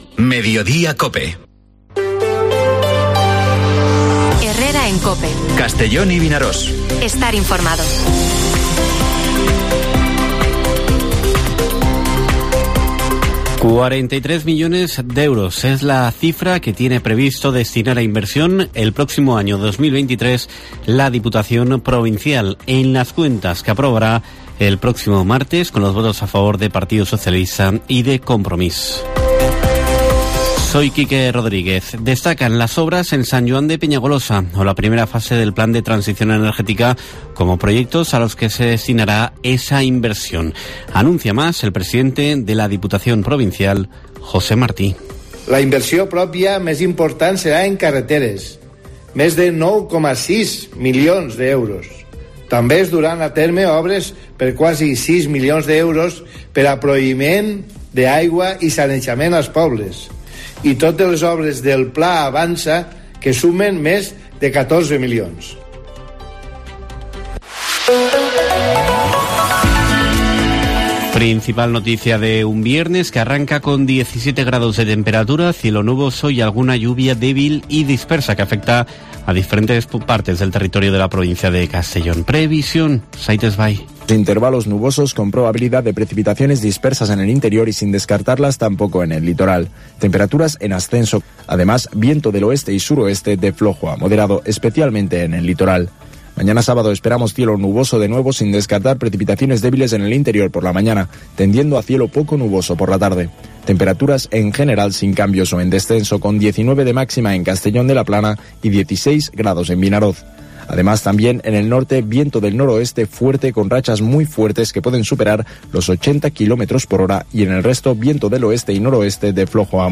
Informativo Mediodía COPE en la provincia de Castellón (09/12/2022)